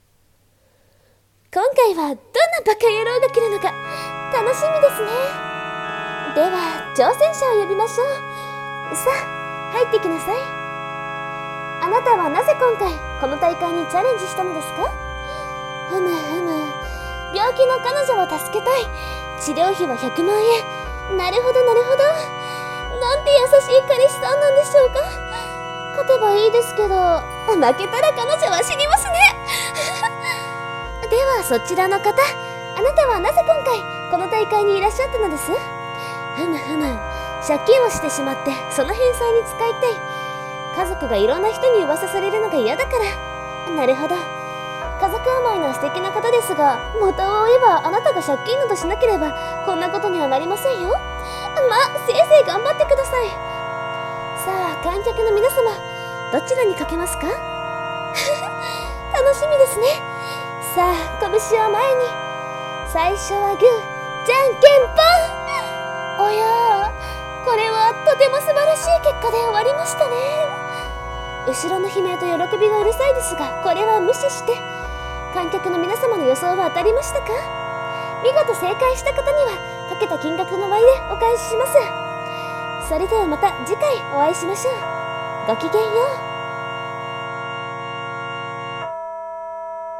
〖声劇〗